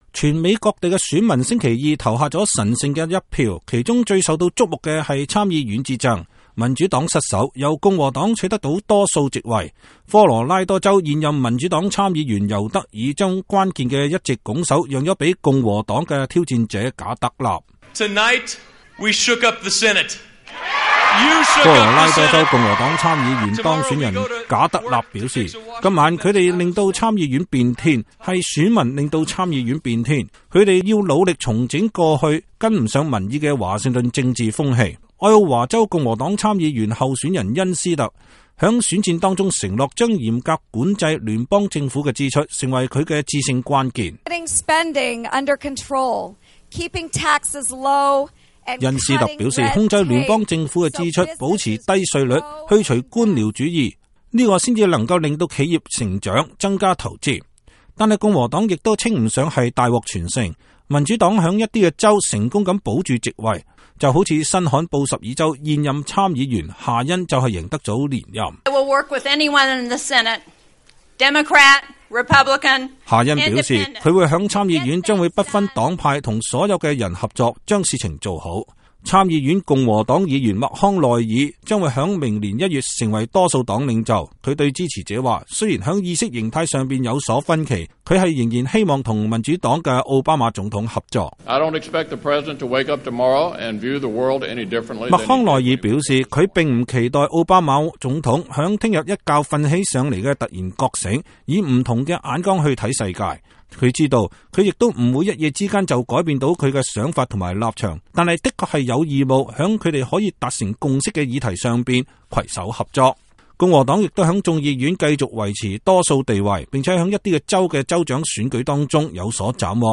2014-11-05 美國之音視頻新聞: 美國中期選舉結果出爐共和黨控制參眾兩院